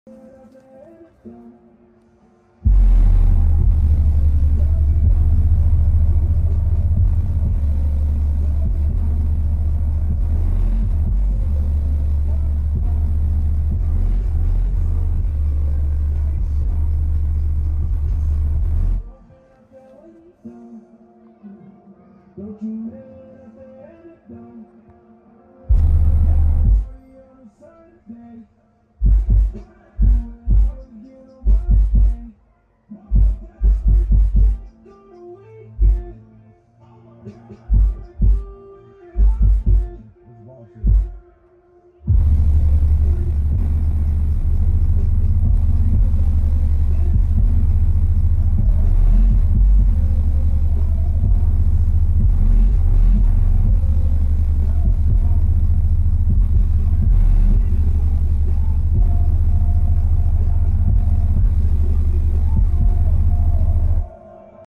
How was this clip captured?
2 out of the 4 subs @4 ohms on 20w rms amp clean power ish#basshead